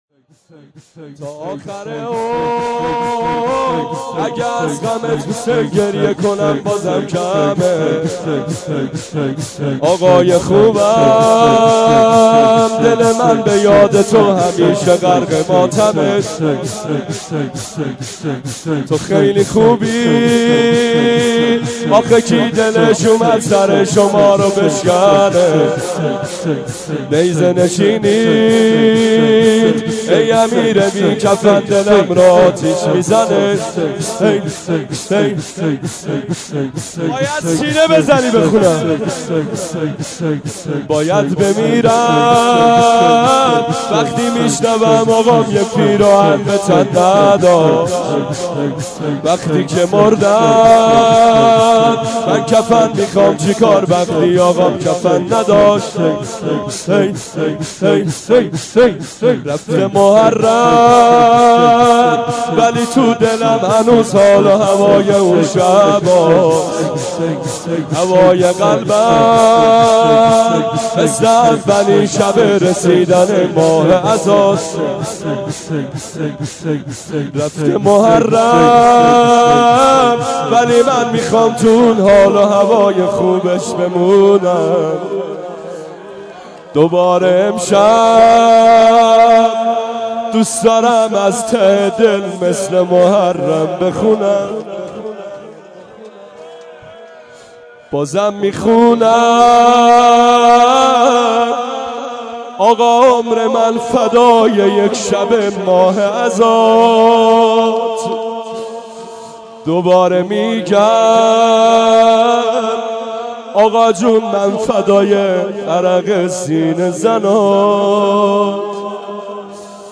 شور | تا آخر عمر
سینه زنی شور مداحی جدید